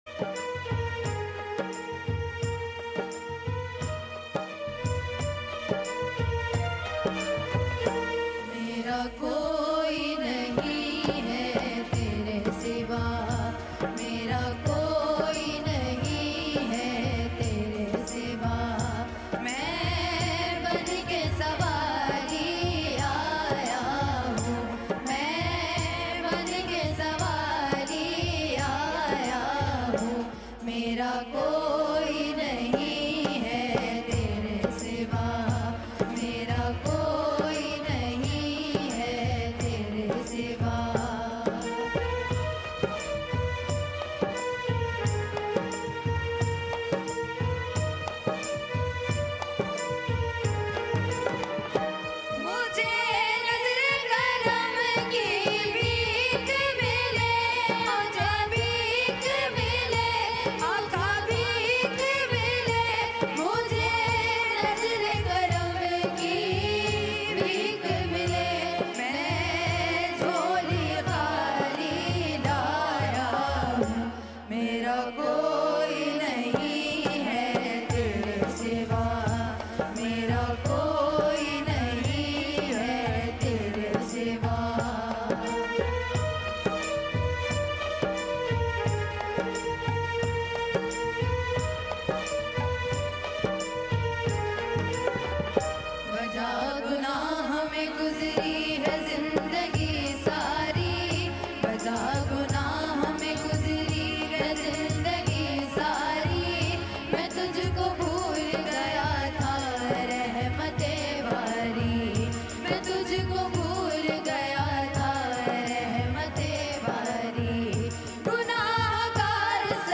Live Ifftar Transmission